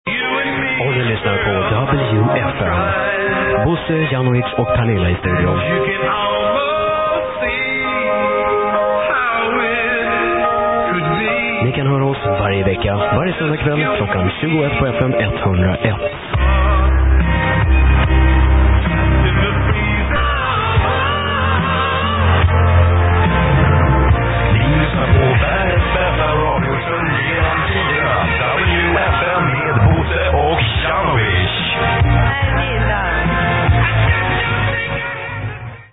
De gjorde som de flesta andra dvs. spelade in programmen hemma och spelade upp dem med en bilbandspelare.